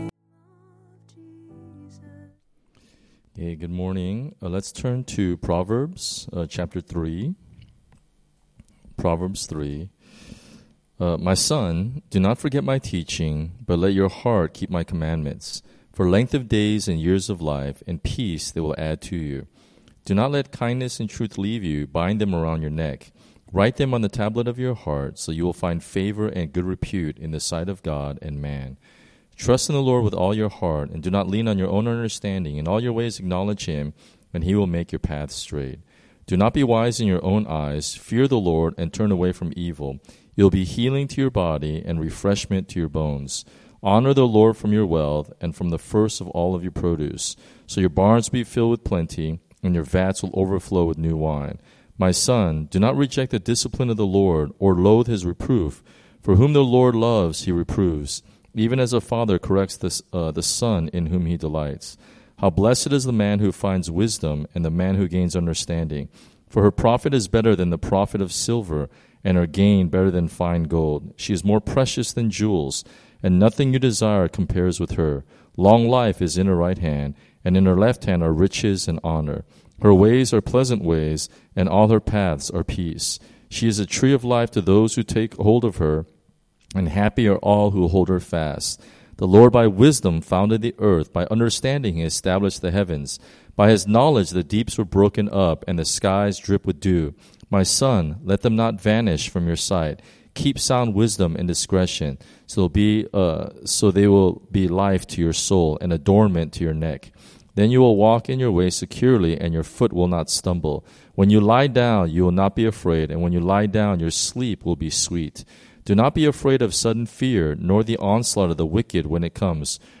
Early Morning Prayer devotionals from Solomon's Porch Hong Kong.